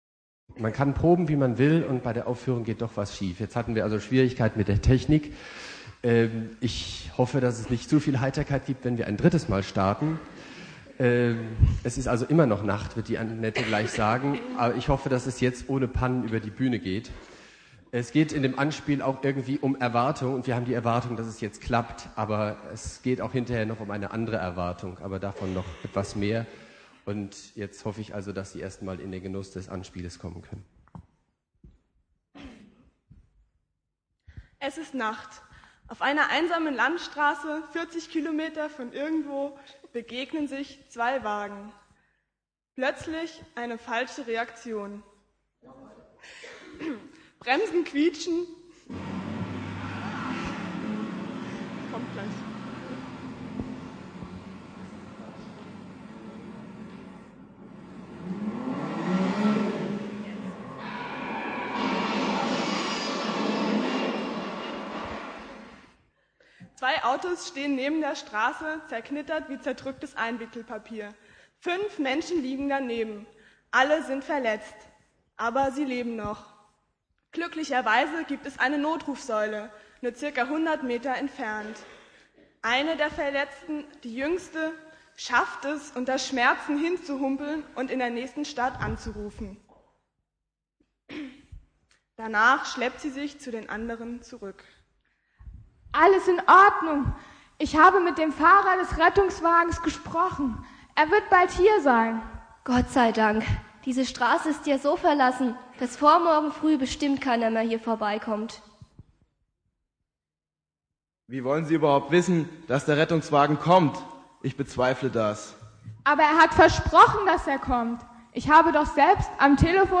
Anspiel und Kurzpredigt
Rufe mich an in der Not - im Rahmen eines Jugendgottesdienstes